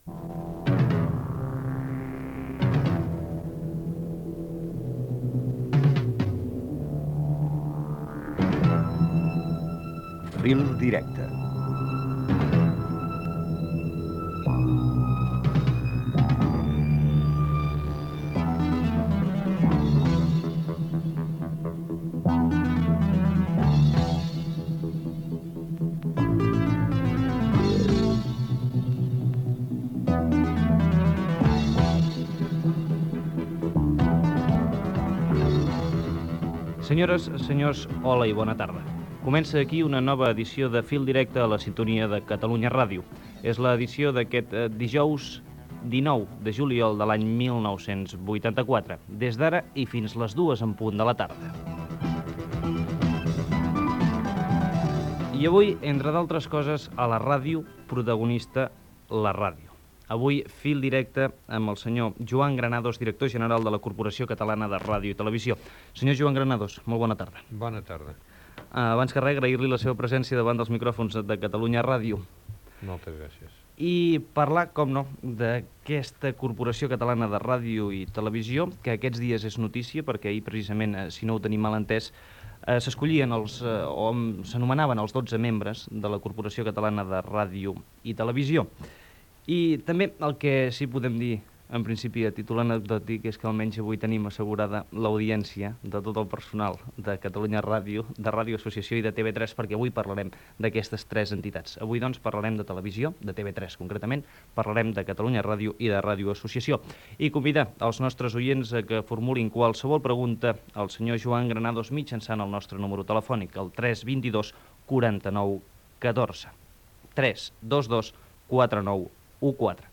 Careta del programa, data, presentació i preguntes, amb participació de l'audiència